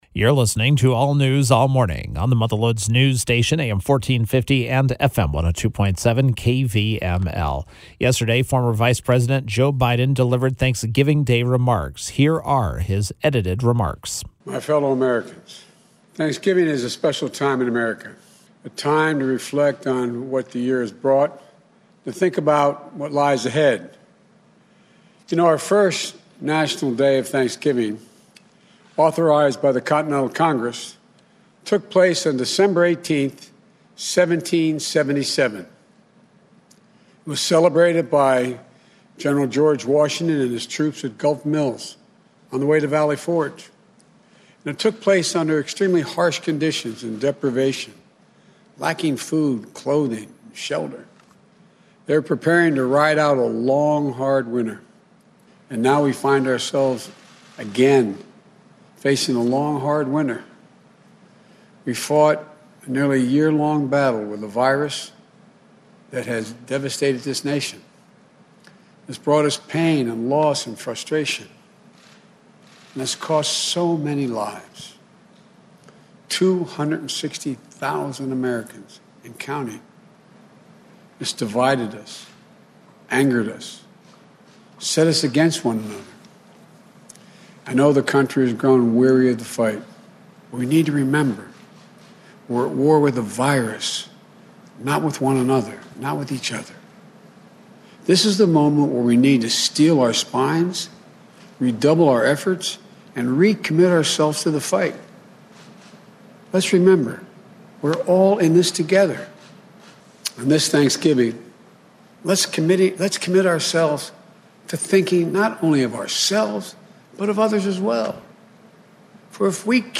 Former Vice President Joe Biden delivered a Thanksgiving Day message.